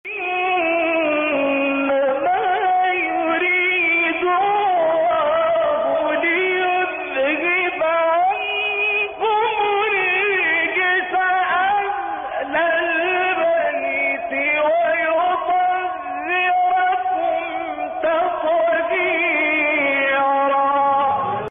به بهانه سالروز تخریب بقاع متبرکه بقیع کرسی تلاوت مجازی با محوریت آیه تطهیر را با صدای راغب مصطفی غلوش، کامل یوسف البهتیمی، شعبان عبدالعزیز صیاد و سیدمتولی عبدالعال از قاریان شهیر جهان اسلام می‌شنوید.
تلاوت آیه تطهیر با صوت شعبان عبدالعزیز صیاد